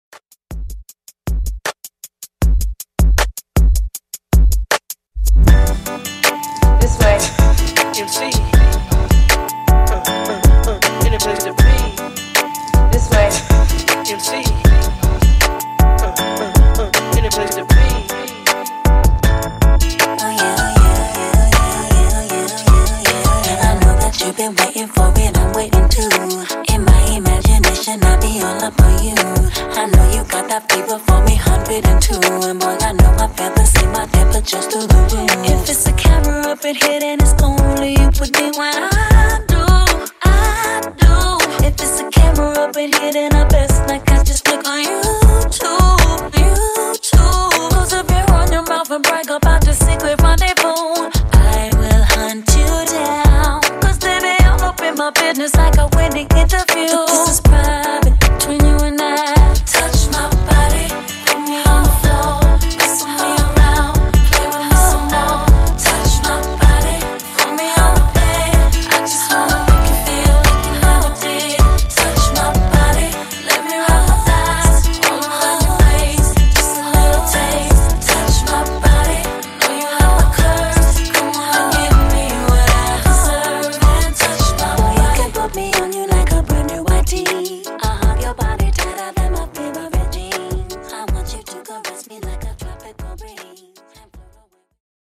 R&B Funky ReDrum